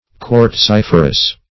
Search Result for " quartziferous" : The Collaborative International Dictionary of English v.0.48: Quartziferous \Quartz*if"er*ous\, a. [Quartz + -ferous.]